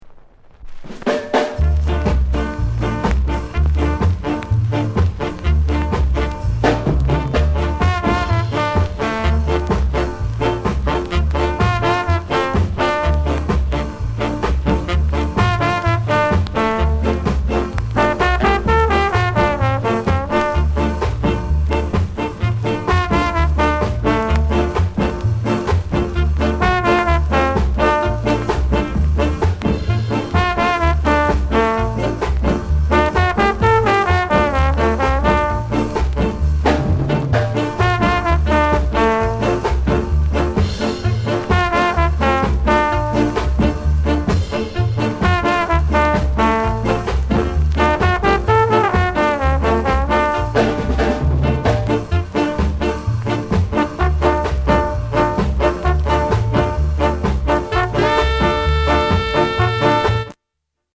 RARE KILLER SKA INST